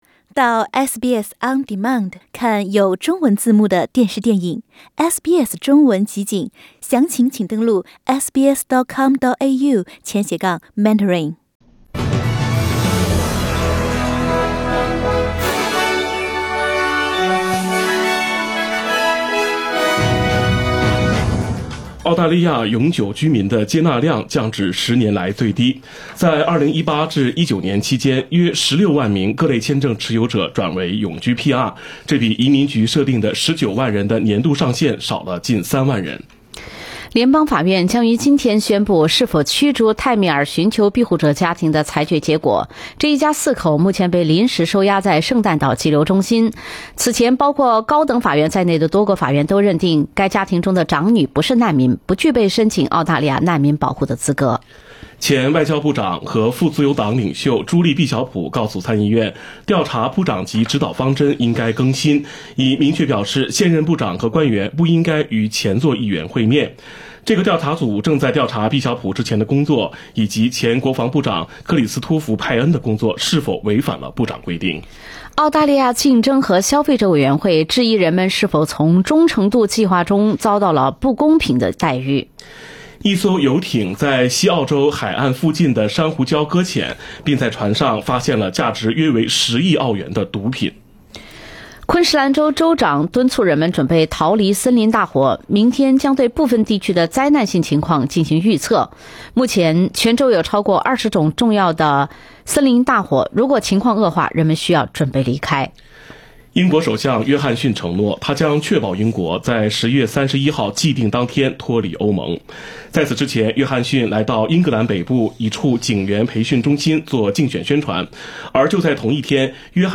SBS早新闻（9月6日）